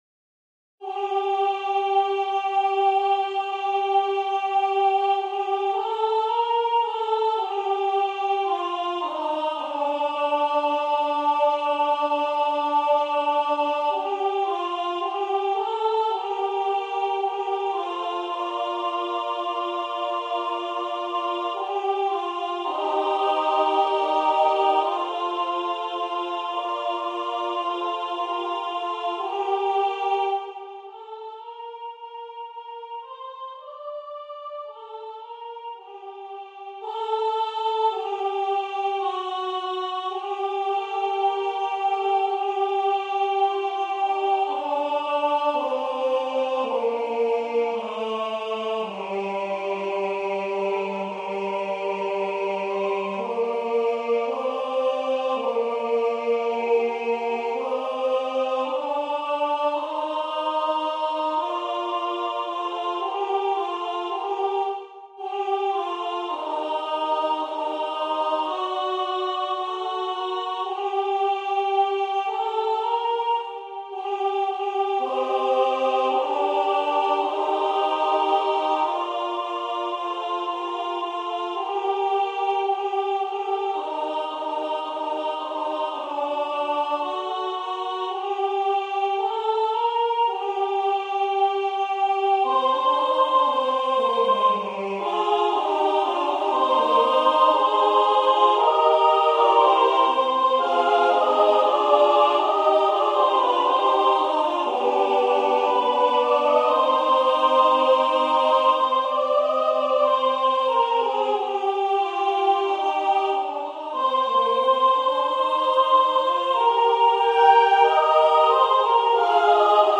Ноты, хор, партитура голосов.
*.mid - МИДИ-файл для прослушивания нот.